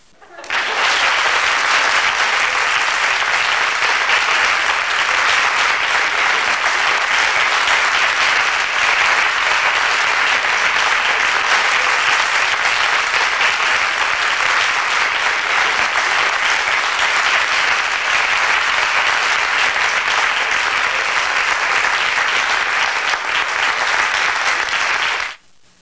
Applause Sound Effect
Download a high-quality applause sound effect.
applause-12.mp3